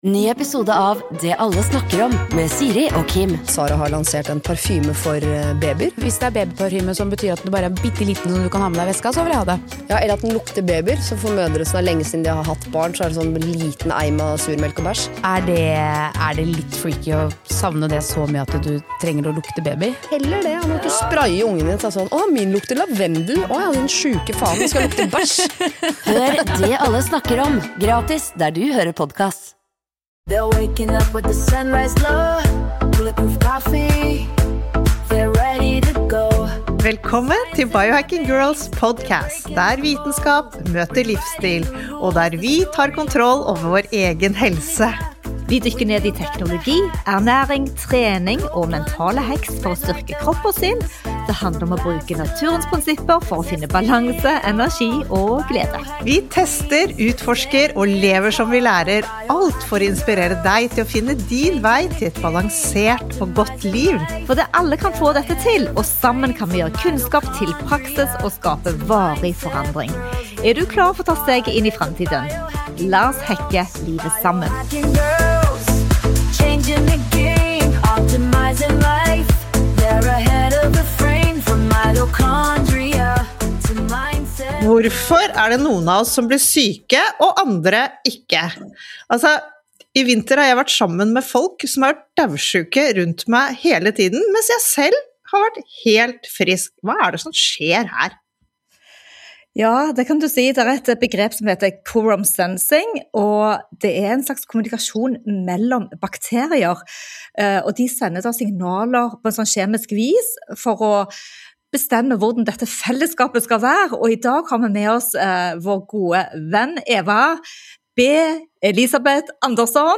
En nerdete, men viktig samtale om mikrobiologi, immunforsvar og hvorfor det noen ganger handler mer om samarbeid mellom mikrober – enn om én enkelt bakterie.